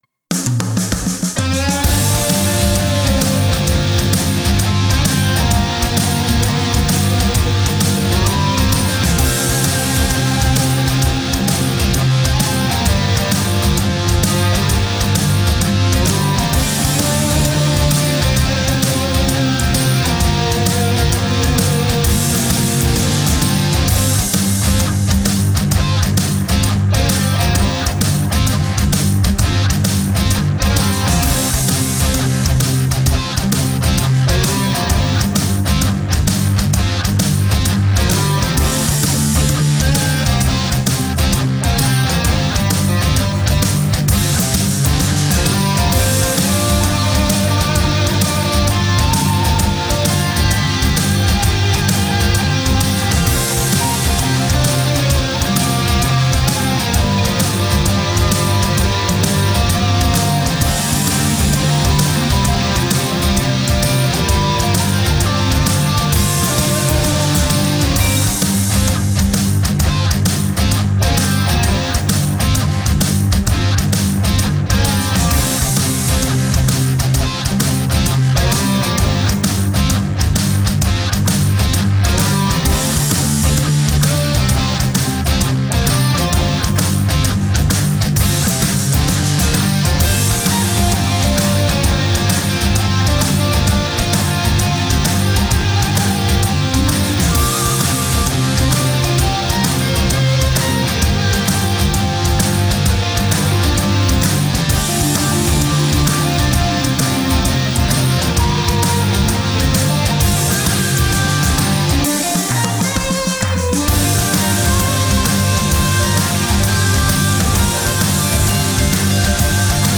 Hop une petite 7 cordes pour changer  :)
C'est une Ibanez RG7 avec des EMG actifs, elle est taillée pour le gros son  :)